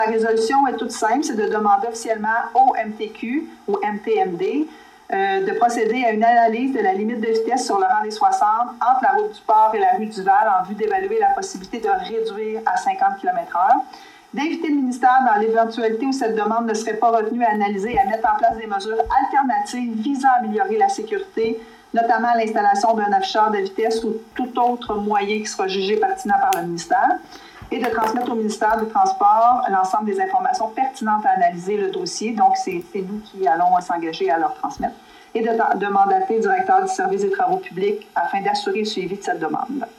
Lors de la séance du conseil de lundi, la mairesse, Geneviève Dubois, a expliqué que la Ville a reçu des demandes de la part des citoyens en raison, entre autres, de la présence d’une garderie.